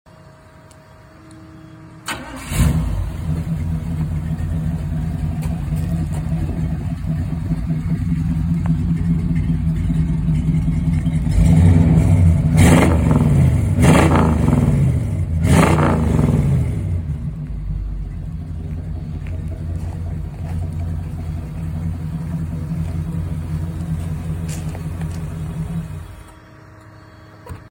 Check out this 55 chevy. We installed patriot Headers, Flowmaster 40’s and 2.5” dual exhaust.